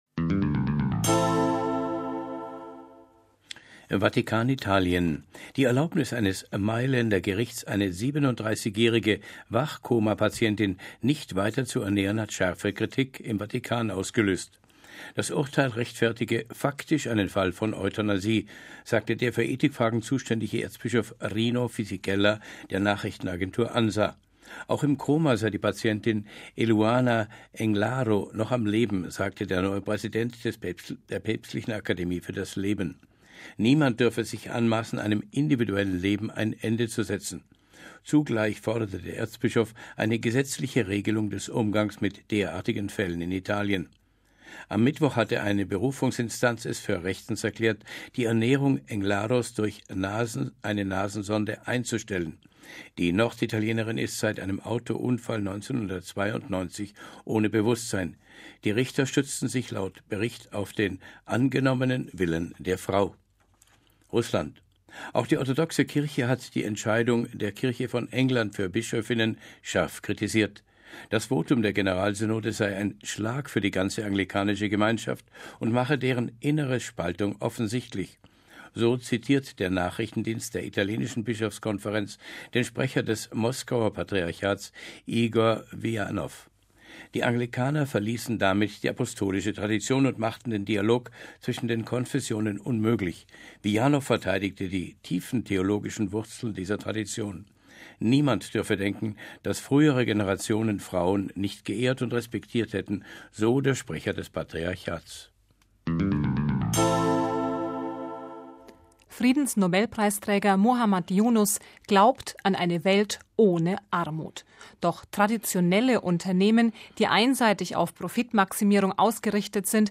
Yunus gegenüber Radio Vatikan: